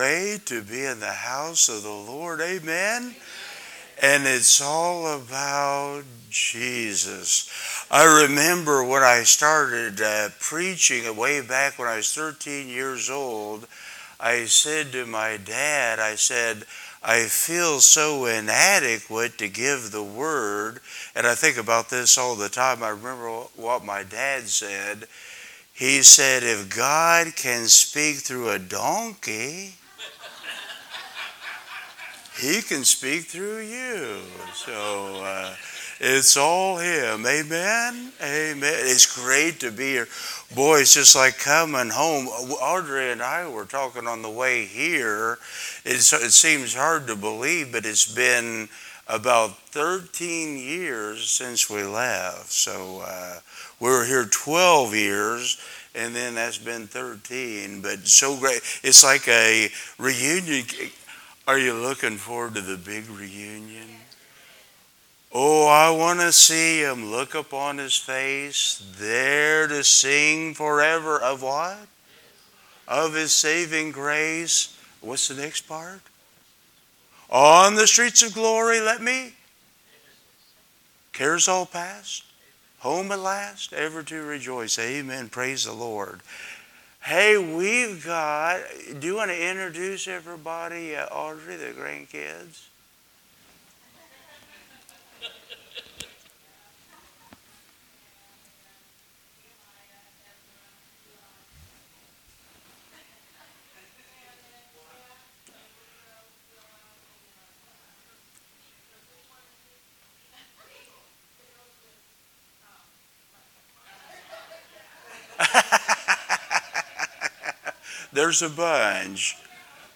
2025 Guest Speaker https